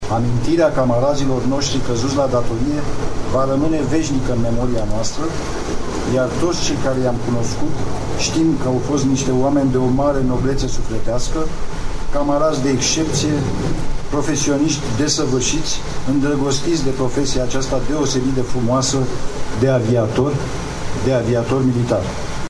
La monumentul ridicat între satul Simon și locul din Bucegi în care a căzut elicopterul, a avut loc o ceremonie de cinstire a celor 7 militari.
Șeful Statului Major al Aviatiei, general maior Viorel Pană: